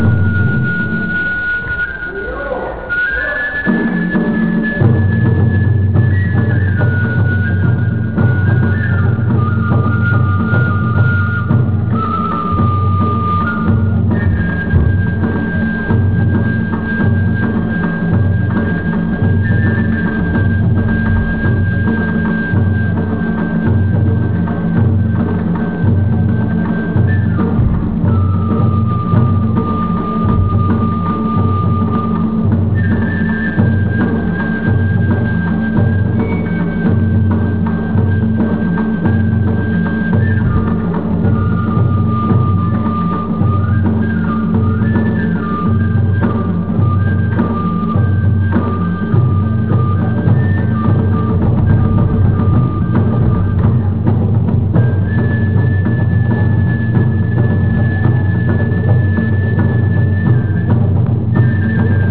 和歌山県の古座町田原の木葉神社（このはじんじゃ。通称・ねんねこの宮）で和歌山県指定無形民俗文化財「ねんねこ祭り」が行われました。
こちらは御神楽の演奏風景。
２つの太鼓を数人で代わる代わるに打ち、笛との合奏で旋律を繰り返してゆきます（御神楽の曲は